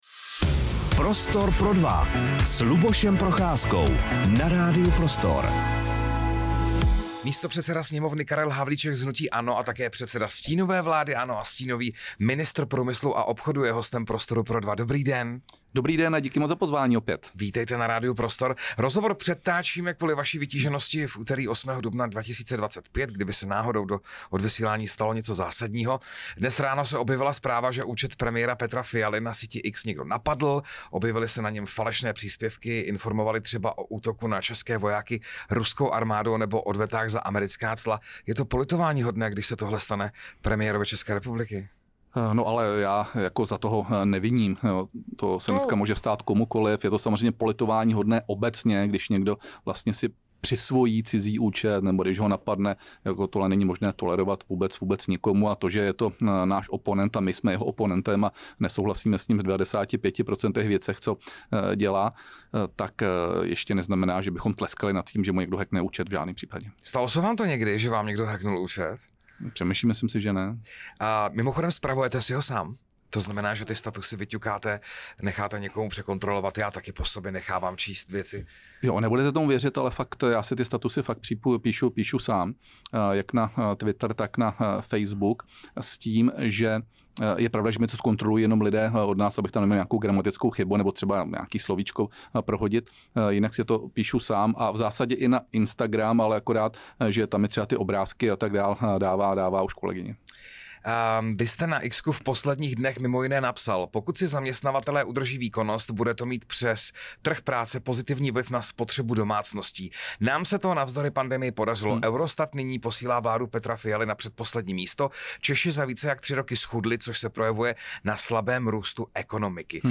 Rozhovor s Karlem Havlíčkem | Radio Prostor